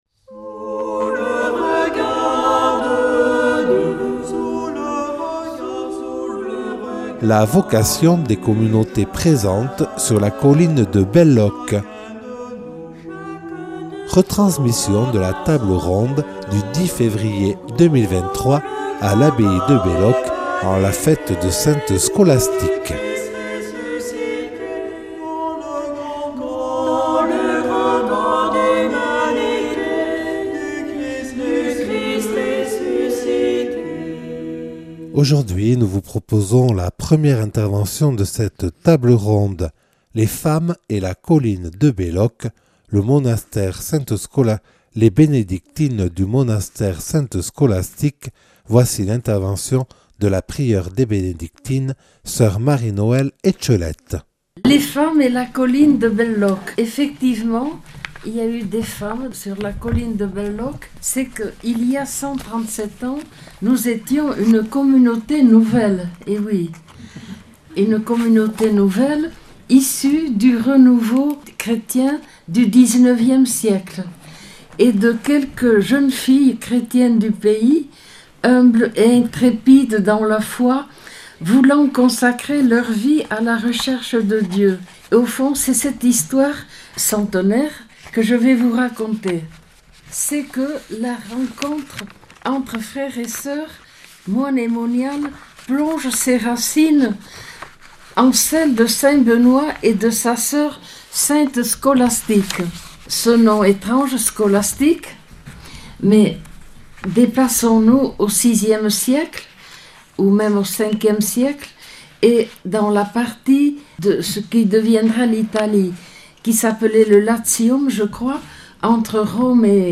Retransmission de la table ronde du 10 février 2023 à l’abbaye de Belloc